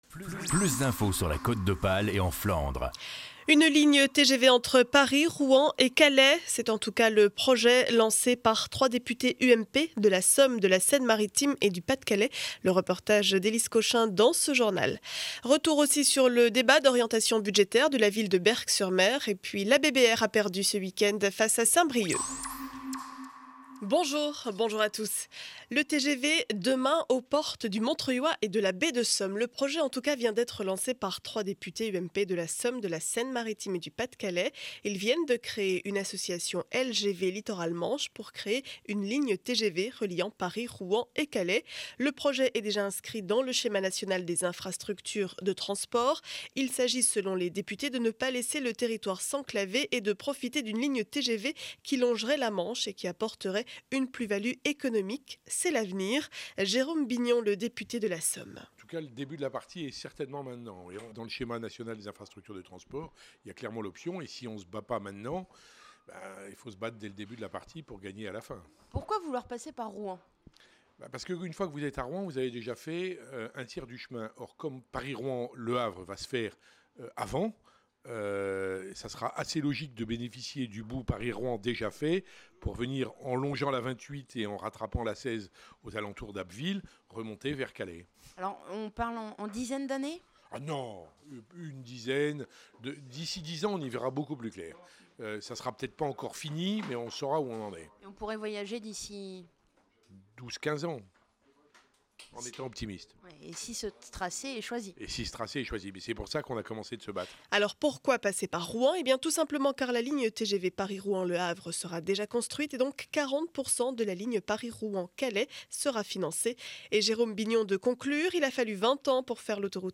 Journal du lundi 05 mars 2012 7 heures 30 édition du Montreuillois.